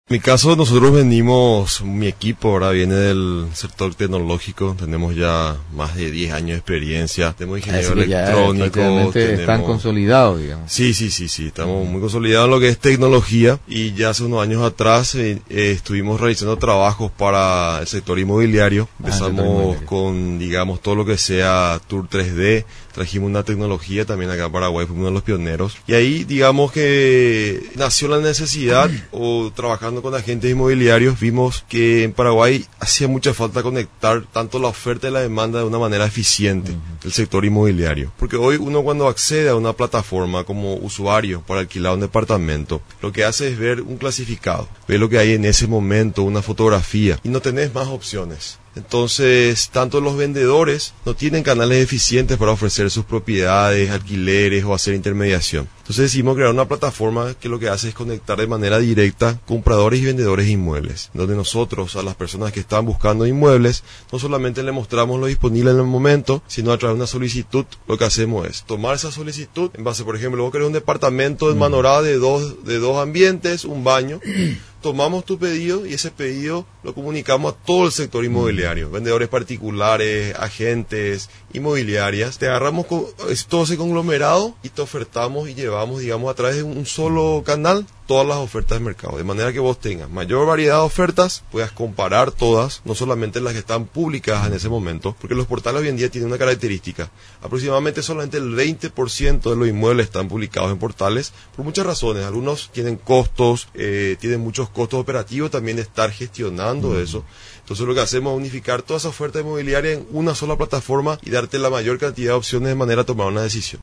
Diseñadores de las plataformas Pavillion y Buscasa, expusieron este viernes en los estudios de Radio Nacional del Paraguay (RNP), 920 Am, sus planes tras haber participado en el concurso de pre-aceleración para empresas emergentes, impulsado por el Ministerio de Tecnologías de la Información y Comunicación (MITIC).